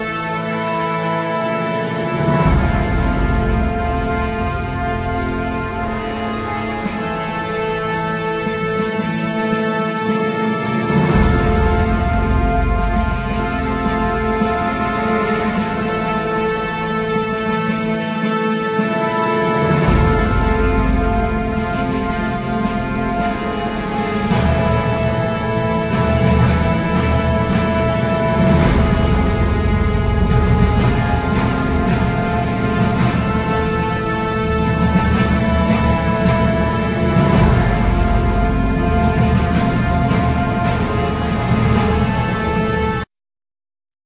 All insturments